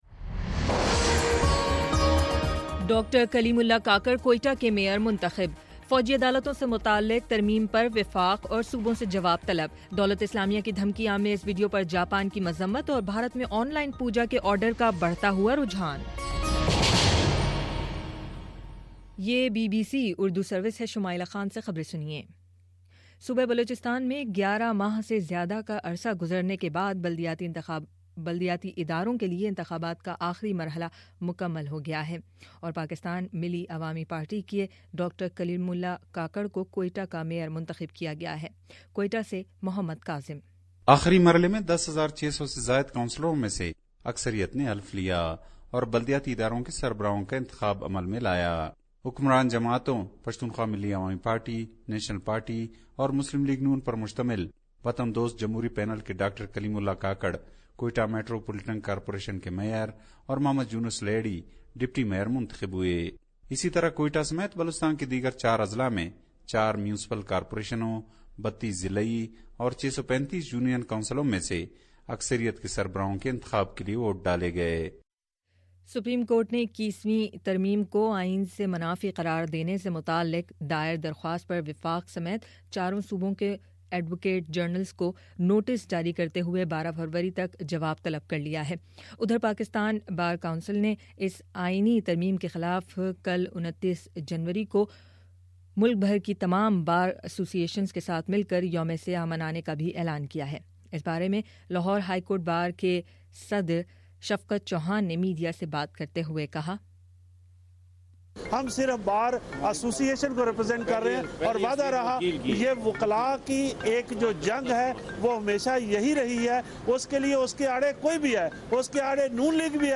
جنوری 28: شام چھ بجے کا نیوز بُلیٹن